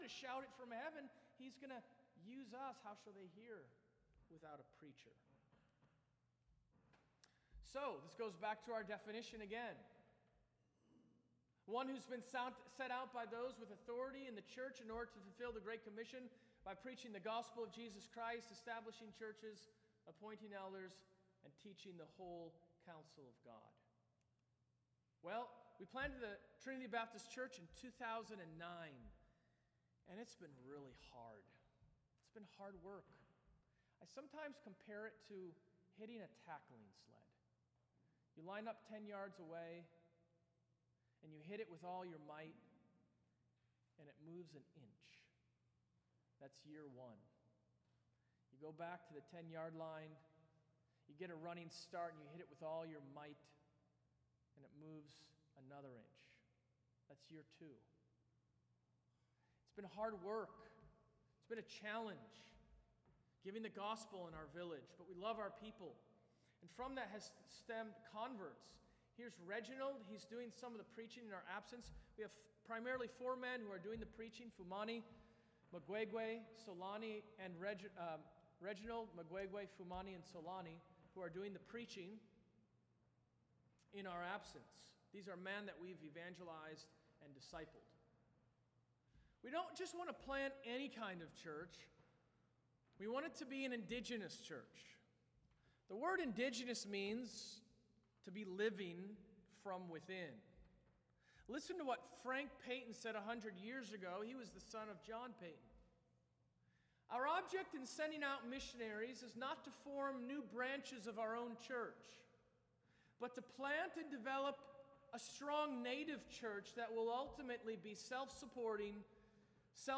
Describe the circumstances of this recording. *Disclaimer, we apologize for the volume jump with the videos.* Service Type: Sunday Evening